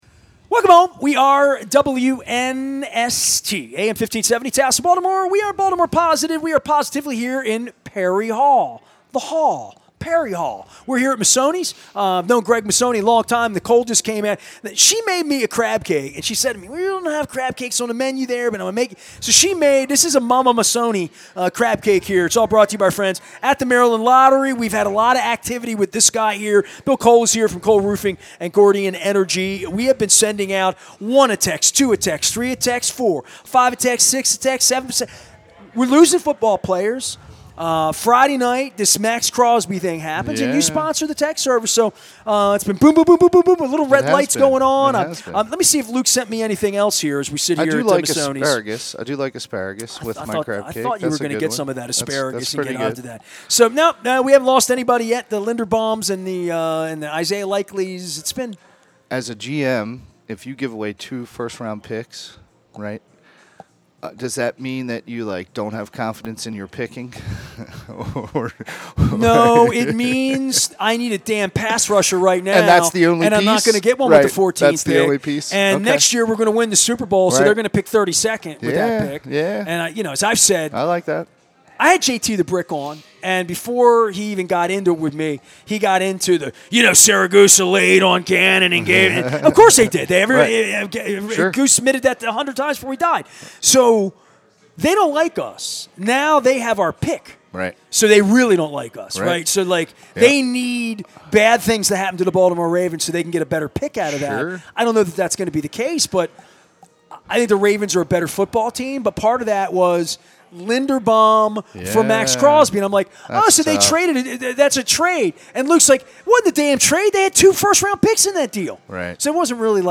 at Massoni’s on Maryland Crab Cake Tour to discuss energy, costs and reality - Baltimore Positive WNST